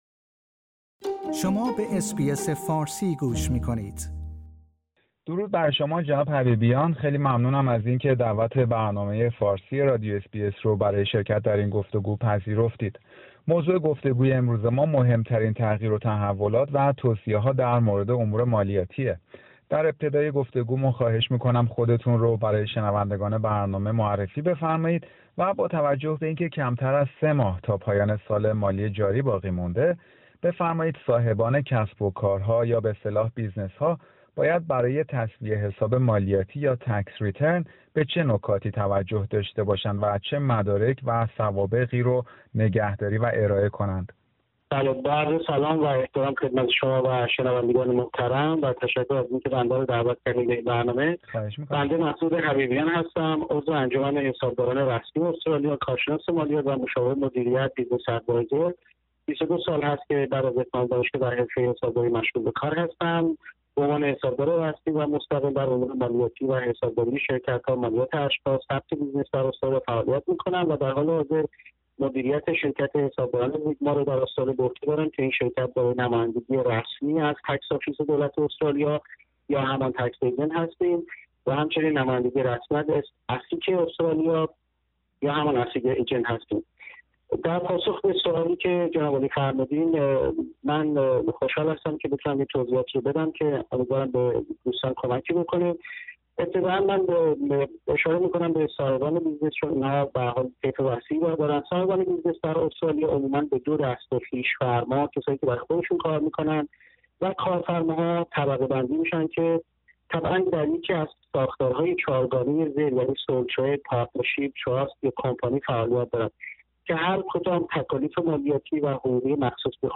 گفتگویی در مورد توصیه های مالیاتی برای صاحبان کسب و کارها